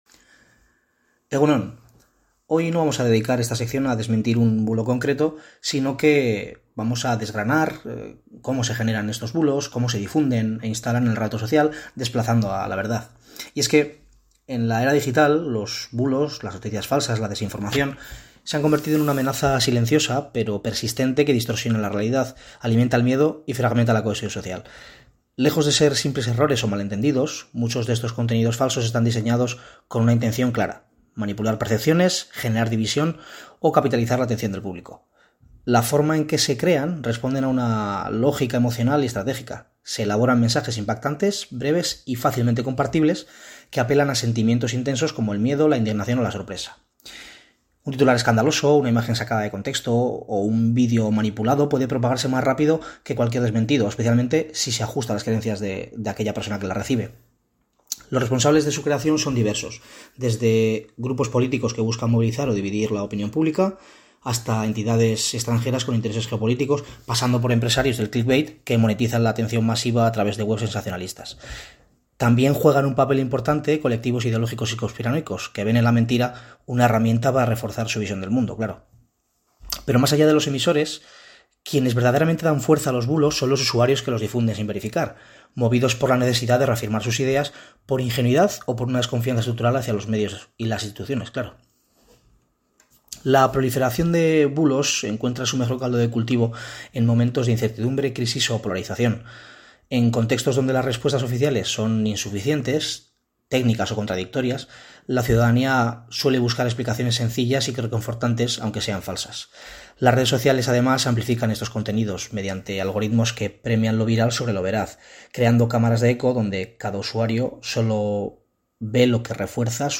En el programa EgunOn Bizkaia de Radio Popular – Herri Irratia se ha abordado esta semana un tema de creciente preocupación social: los bulos y la desinformación en la era digital.